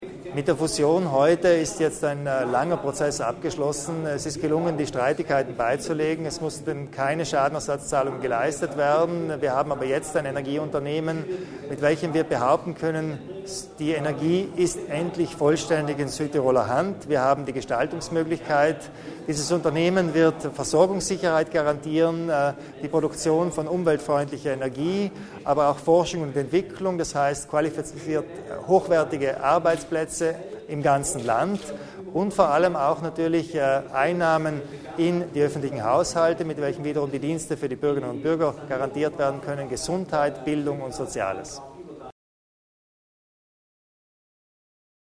Landeshauptmann Kompatscher zur Bedeutung von ALPERIA